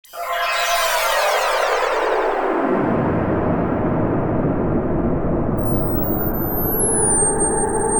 描述：The idea is to give the impression of a strange object movement, like a starship. The sound was made from a tone DFTM to which I applied a reversal of direction, a reverberation , while changing the speed and height (working on the bass and treble) Selon Schaeffer: N continu toniqueMasse: son seul toniqueAllure: vivanteTimbre: BrillantGrain: légèrement granuleuxDynamique: attaque douce et lenteProfil mélodique: SerpentineProfil de masse: Calibre
标签： starship speech strange alien sciencefiction atmosphere
声道立体声